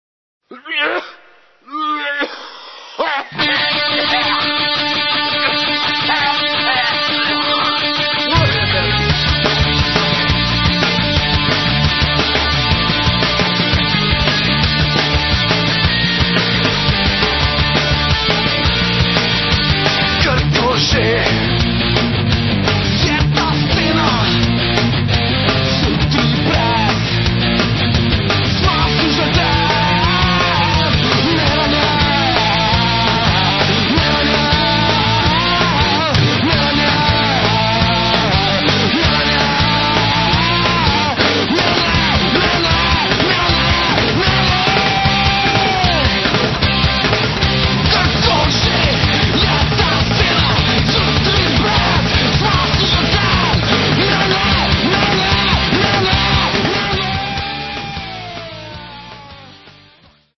spremljevalni vokali
saksofonom